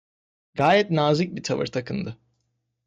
Pronounced as (IPA) /ta.vɯɾ/